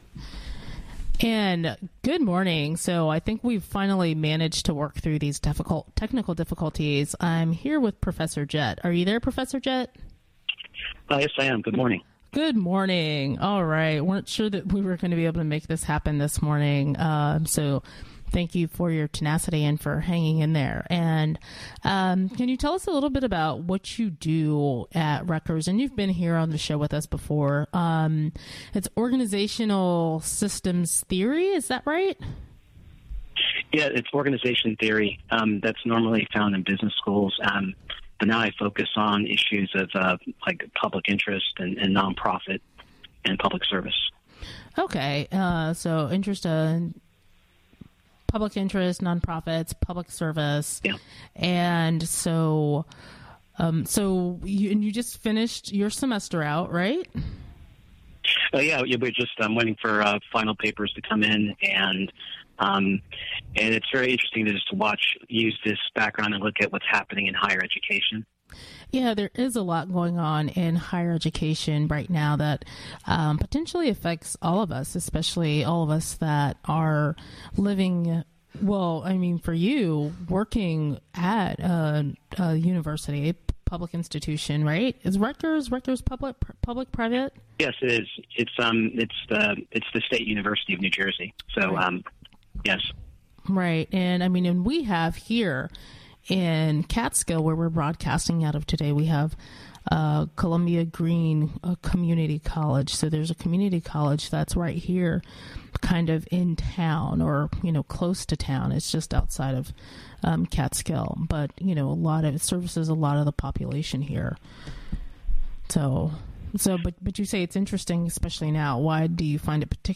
Interview
Recorded during the WGXC Morning Show., Tue., Dec. 15, 2015.